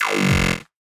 Glitch FX 01.wav